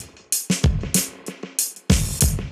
Index of /musicradar/dub-designer-samples/95bpm/Beats
DD_BeatA_95-03.wav